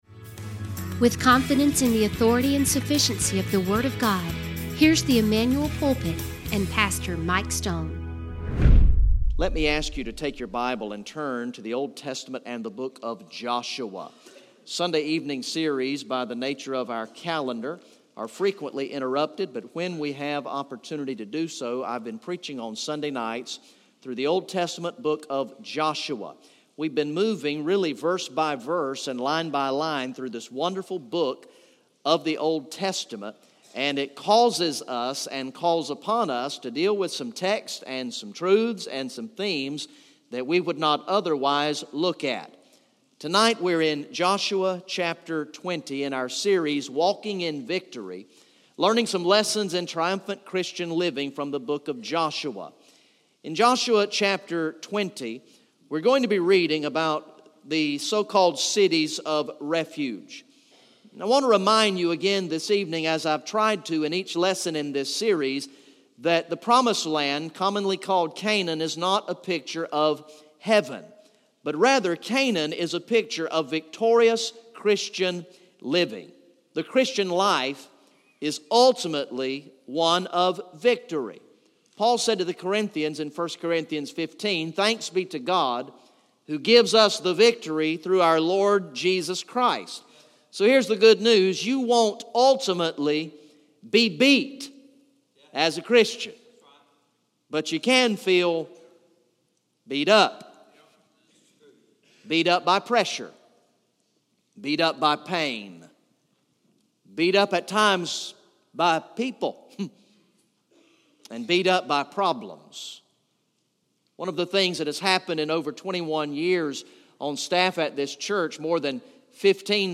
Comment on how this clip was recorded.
Message #22 from the sermon series through the book of Joshua entitled "Walking in Victory" Recorded in the evening worship service on Sunday, December 3, 2017